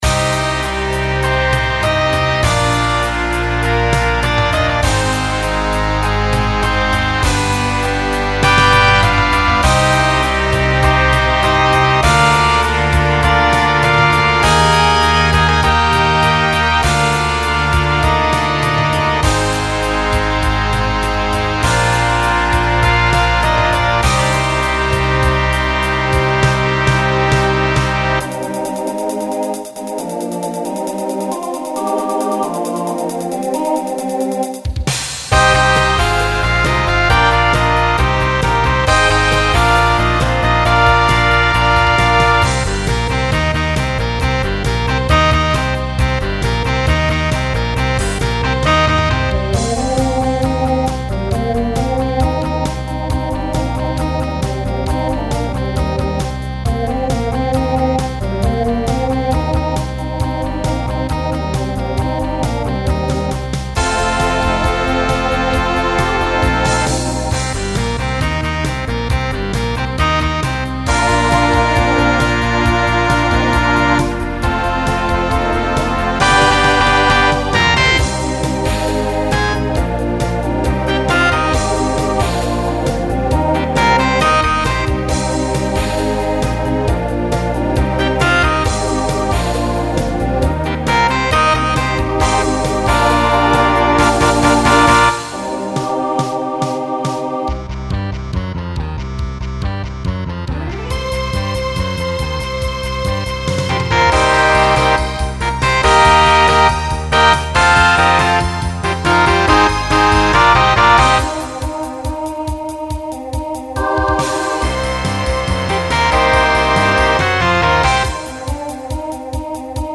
Genre Rock
Transition Voicing Mixed